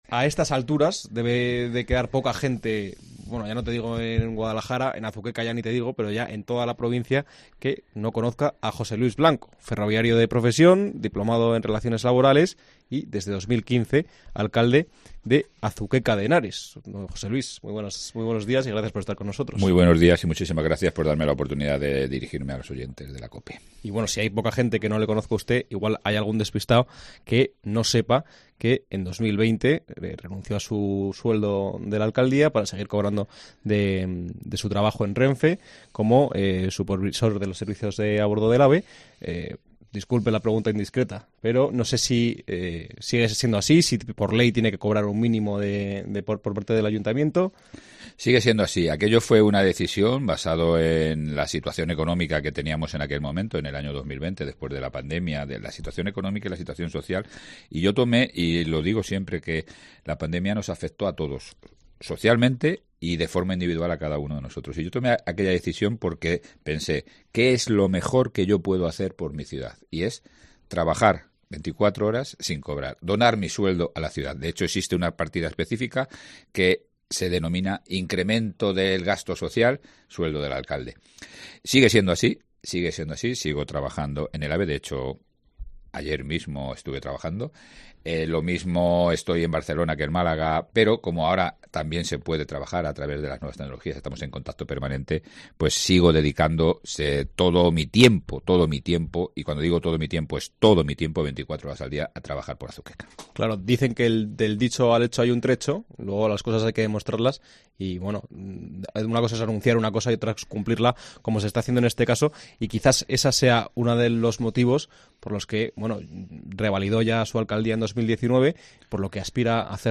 El alcalde de Azuqueca de Henares pasa por los micrófonos de COPE Guadalajara en su camino hacia su tercera legislatura consecutiva